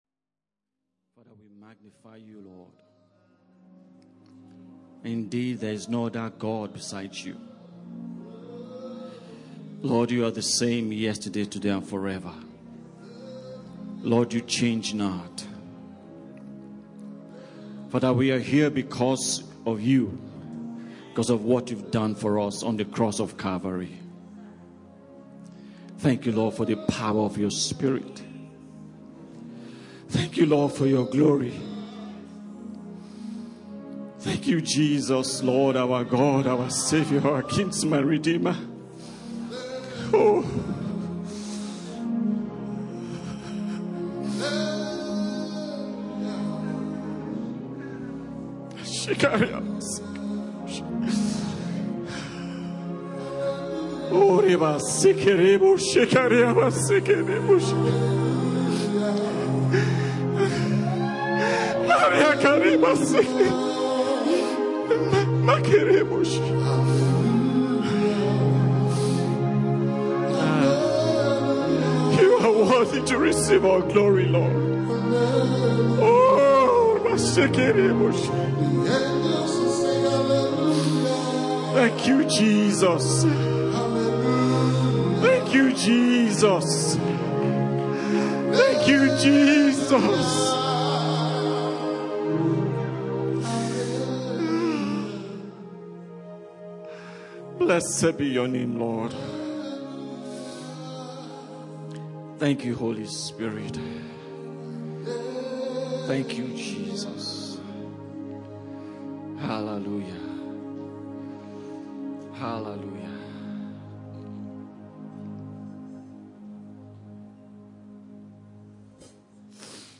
GMI Church, Gospel Missions International Church in Phoenix Arizona, Churches in Phoenix Arizona USA, Phoenix Impact Center